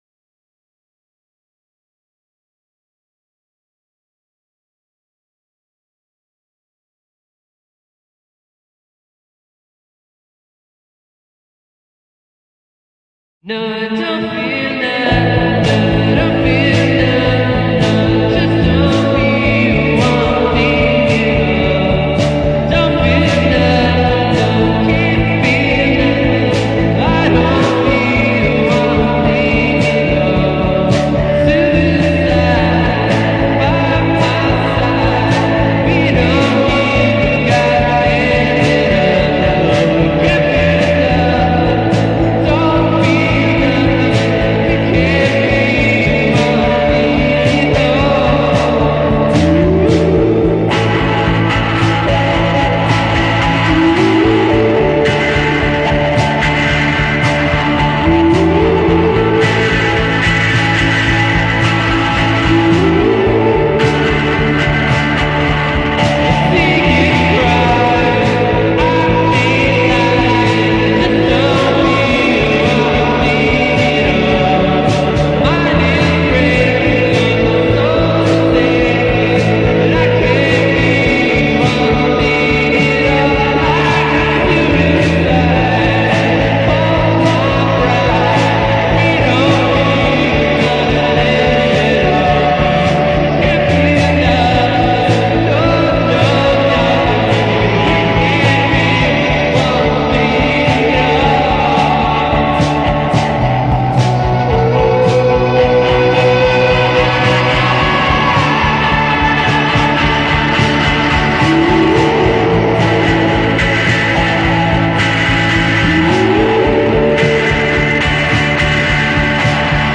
This is music to melt by.